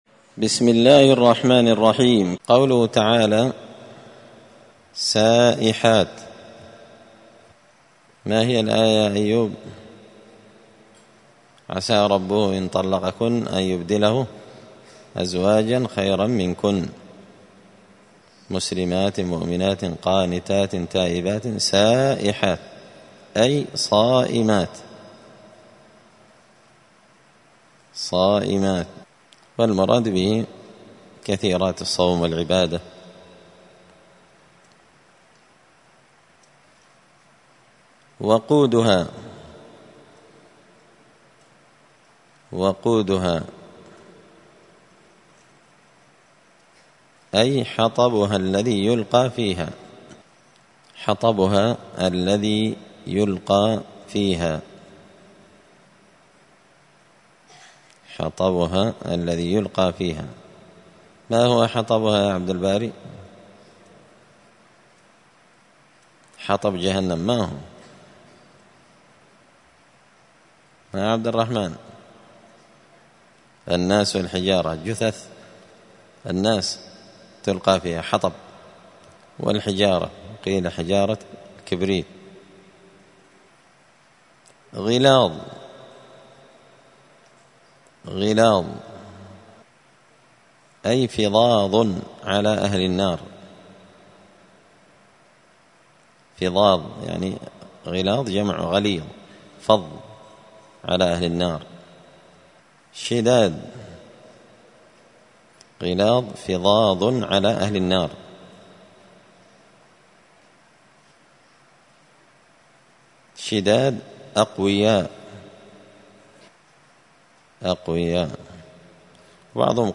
الخميس 4 رمضان 1445 هــــ | الدروس، دروس القران وعلومة، زبدة الأقوال في غريب كلام المتعال | شارك بتعليقك | 26 المشاهدات